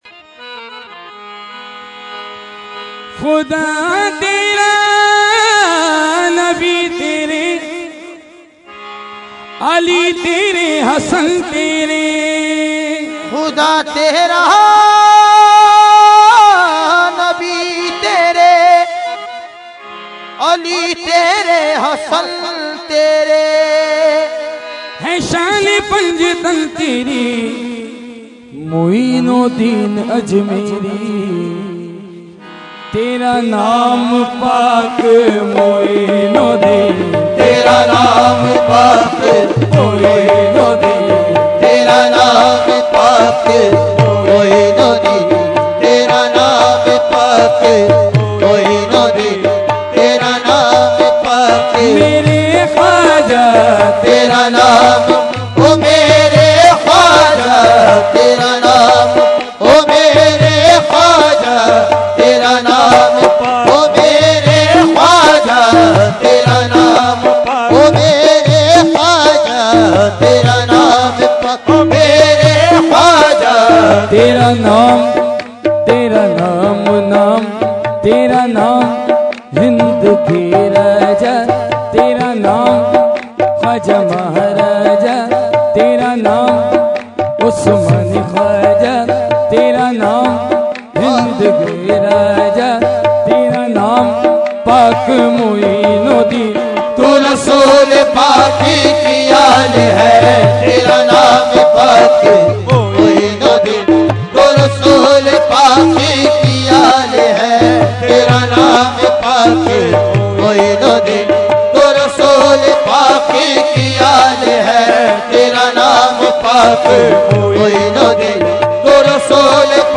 Category : Qawali | Language : UrduEvent : Urs Makhdoome Samnani 2012